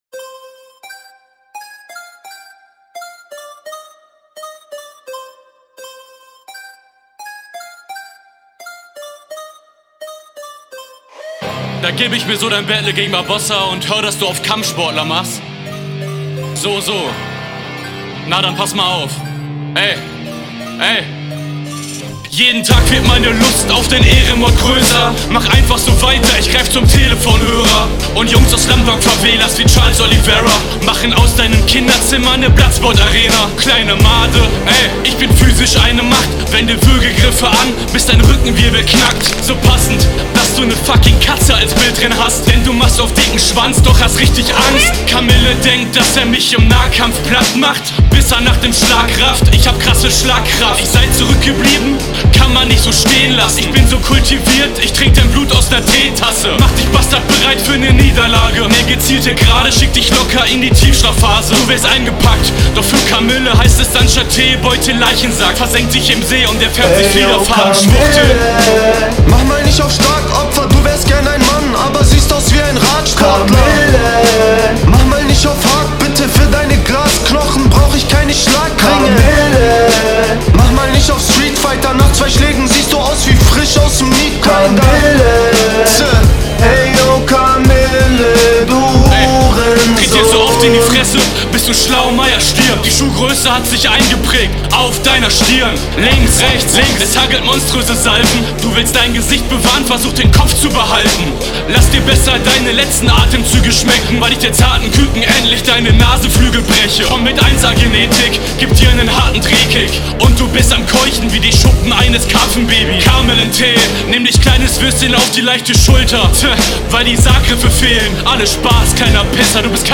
Geile Hook
interessante beatwahl, nicht viel gegnerbezug dafür aber dope spits, sehr schönes gesamtpaket